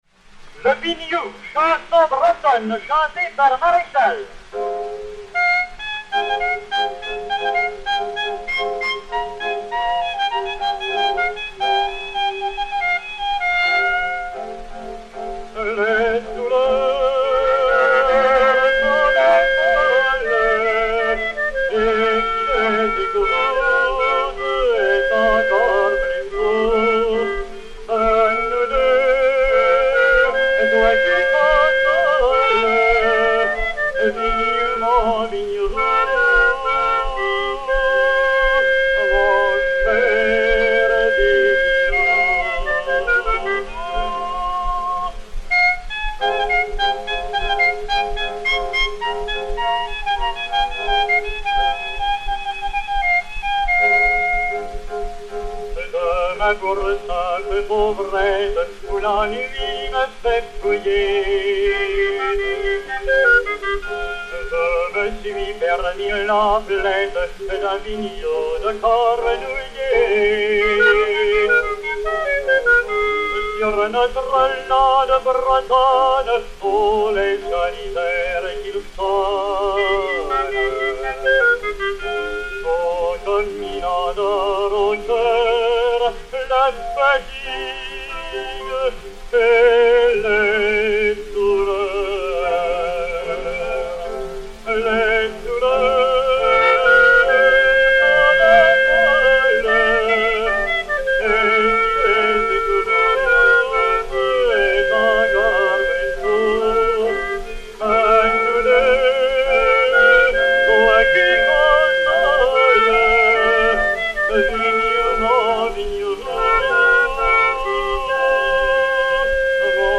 chanson bretonne (par.
piano et hautbois